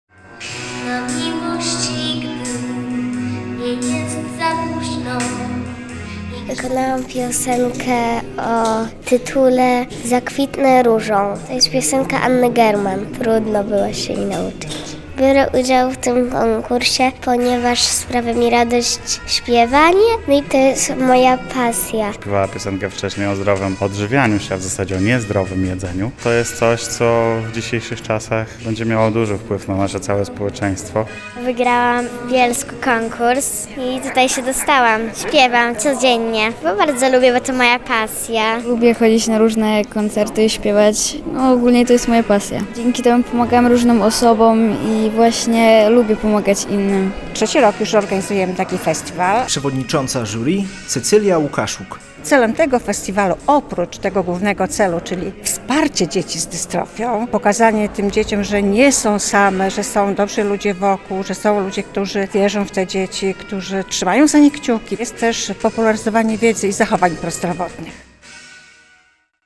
Trwa Festiwal Dziecięcej Piosenki Prozdrowotnej "Śpiewające Serca" w Białymstoku